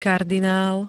kardinál [-d-] -la pl. N -li m.
Zvukové nahrávky niektorých slov